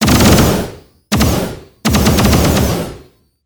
Laser Multiple shots 1.wav